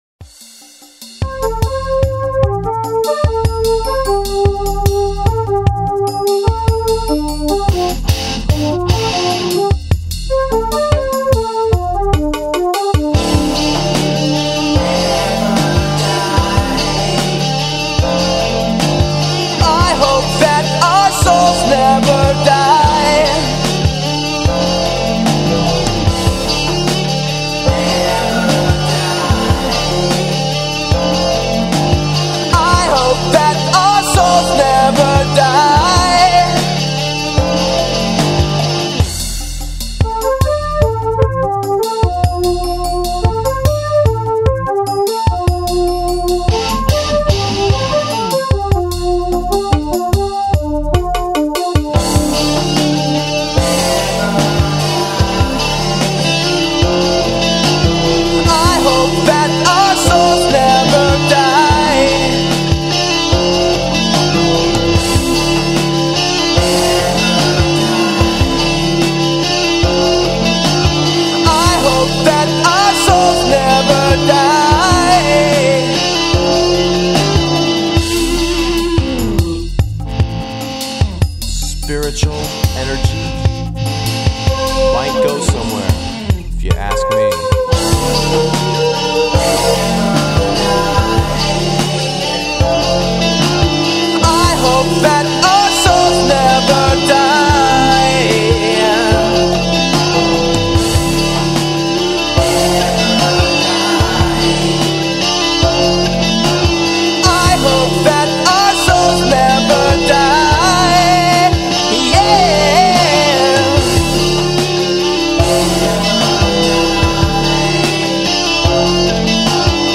Polyrhythmic, eclectic, very modern 'art funk-rock' music.